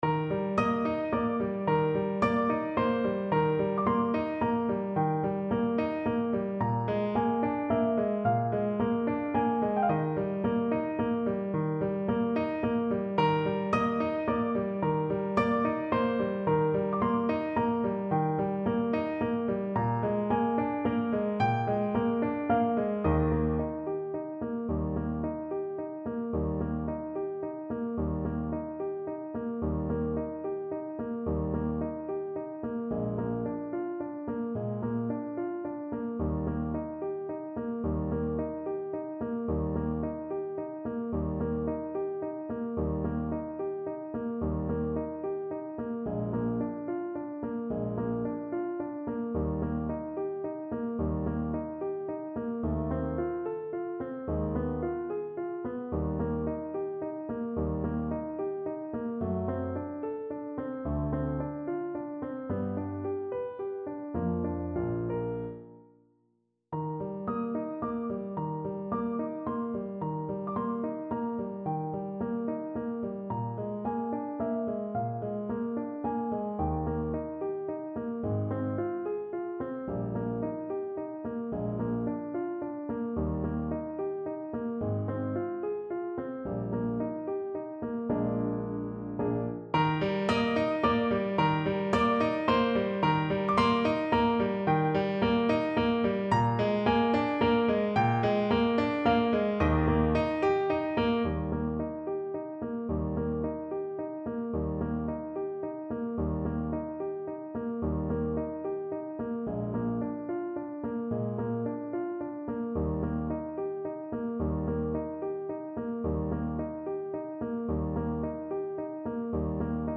Andantino . = c.50 (View more music marked Andantino)
3/8 (View more 3/8 Music)
Classical (View more Classical Clarinet Music)